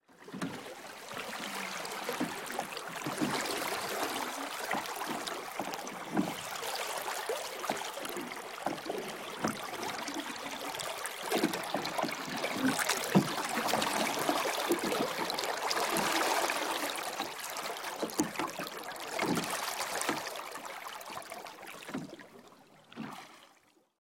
水 海 潟湖 波浪
您可以让孩子们在背景中的水中和珊瑚礁前面的大波浪中玩耍，用Olympus LS100录制平板。
标签： 风景 海滩 留尼汪 海洋 波浪 性质 现场记录 环境
声道立体声